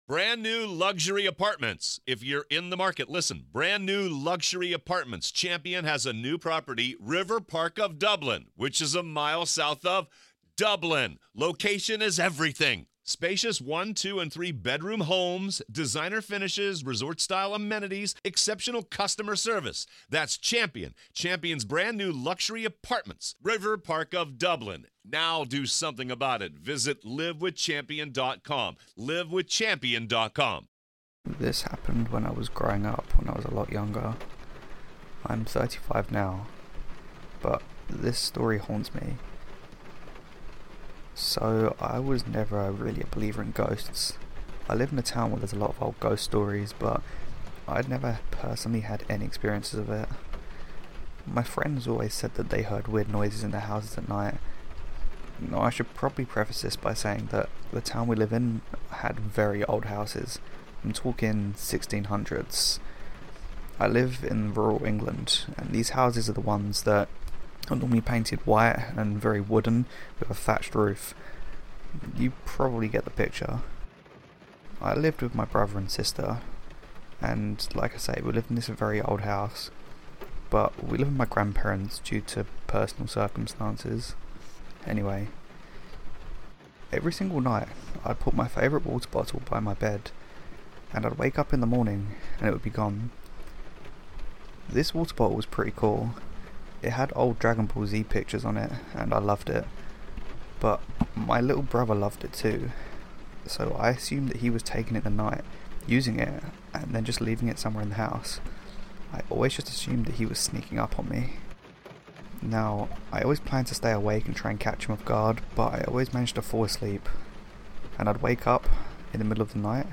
I am back now with 2 new scary ghost stories with rain ambience
Stories written and narrated by myself